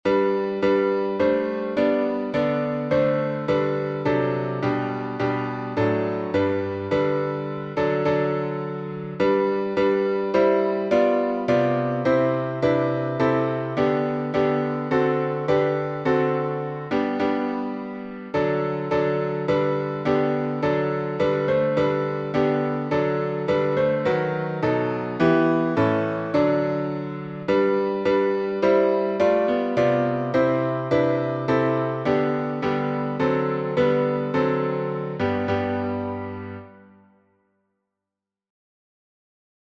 Key signature: G major (1 sharp) Time signature: 4/4 Meter: 8.7.8.7.D.